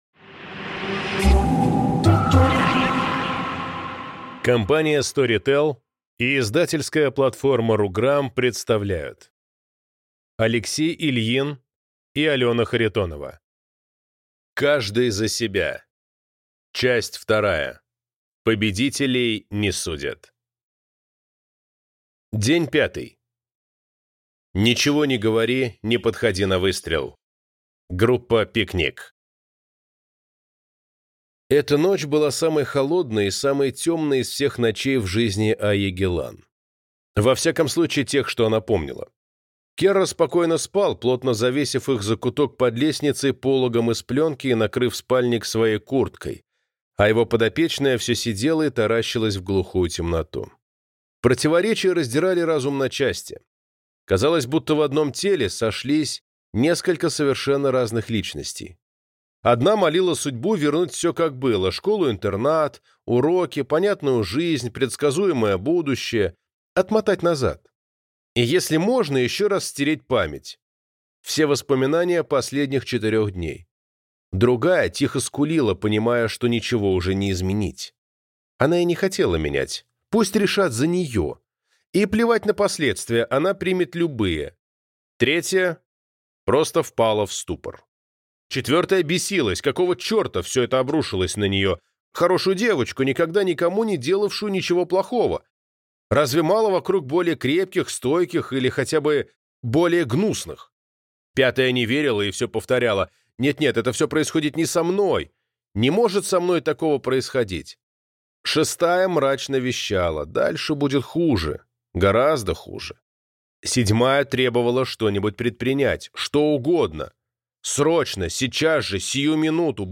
Аудиокнига Каждый за себя. Победителей не судят | Библиотека аудиокниг